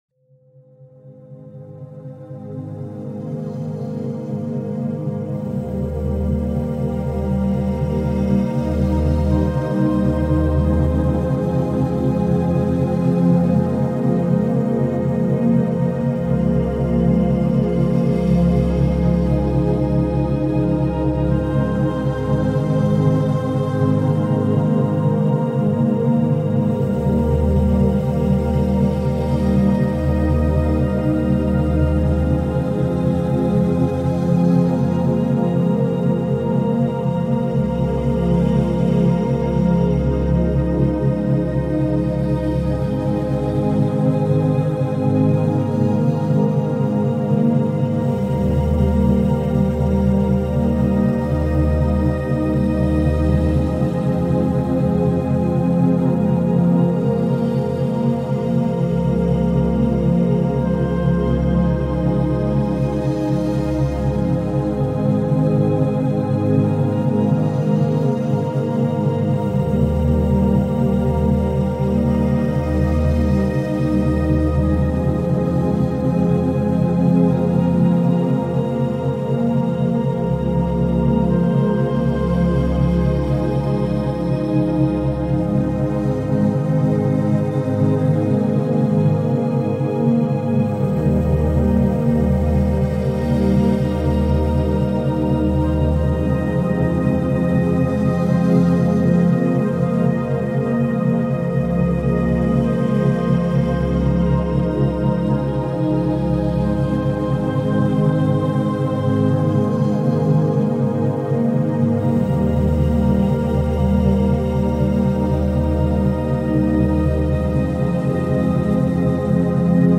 Session longue immersive · méthode piano scientifique avec pauses optimales